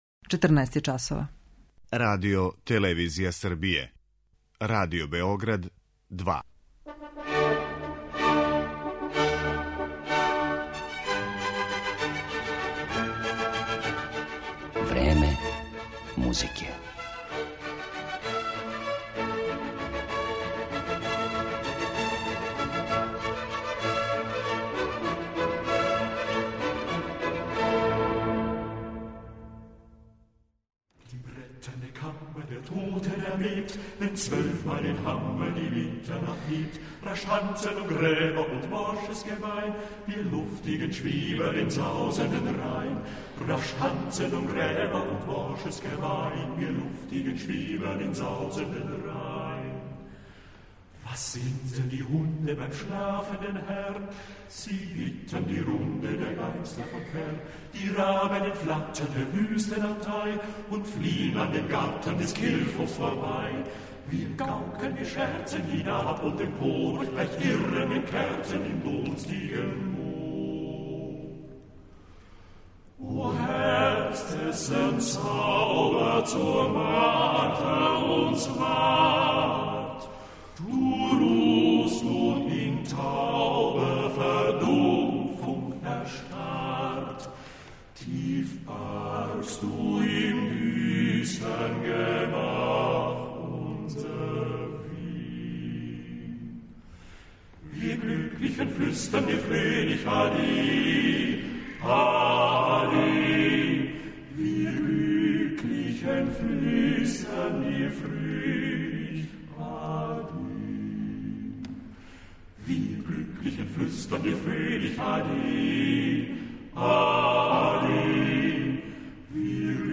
Мушком вокалном саставу